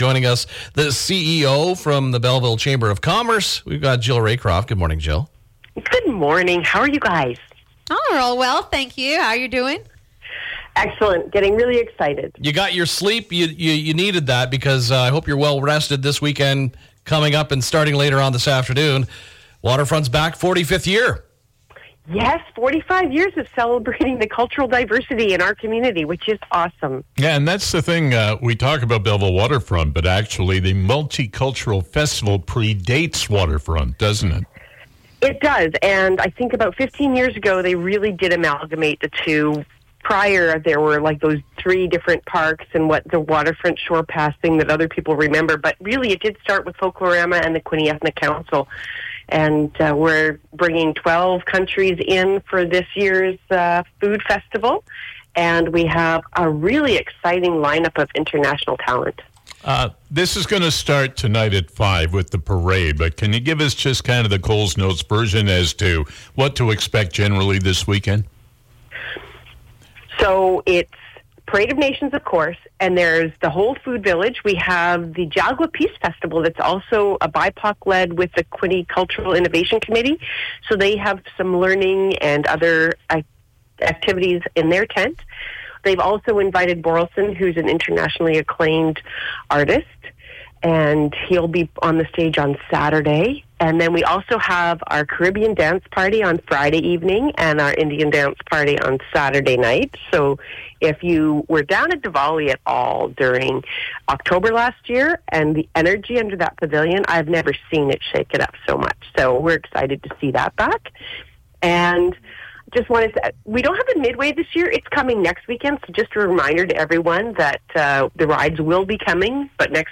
Belleville, ON, Canada / Mix 97